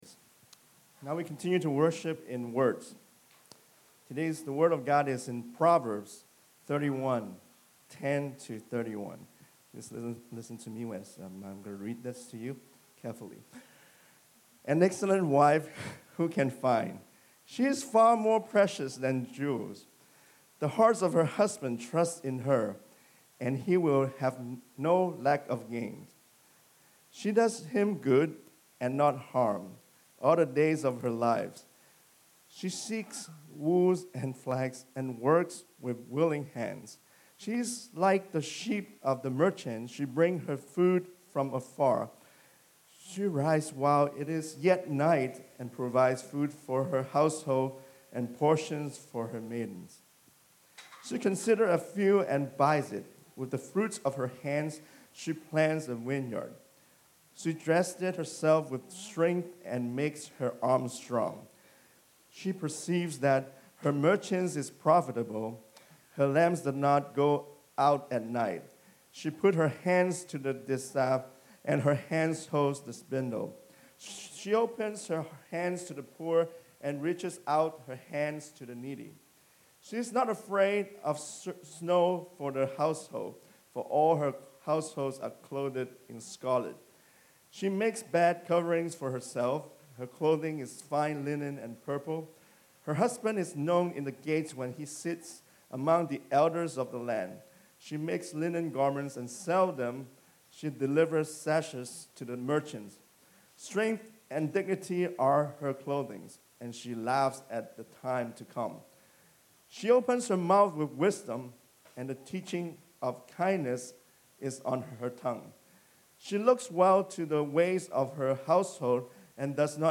English / Mandarin